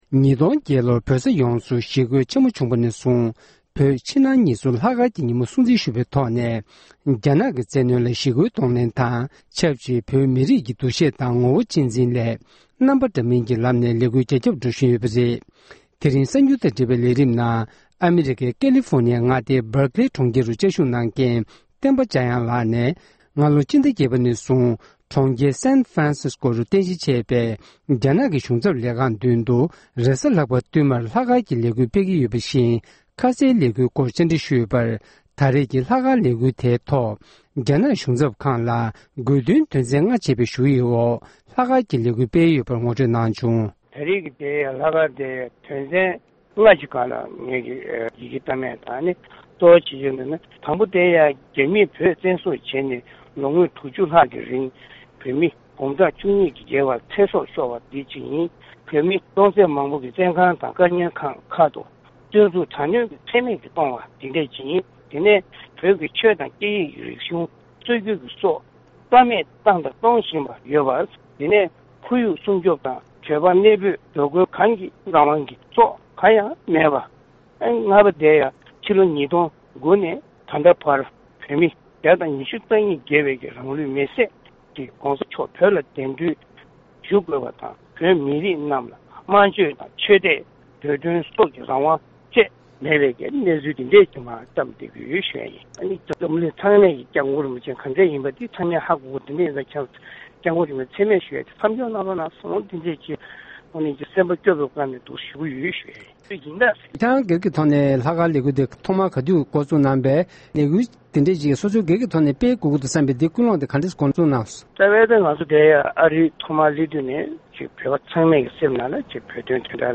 གསར་འགྱུར་དཔྱད་གཏམ།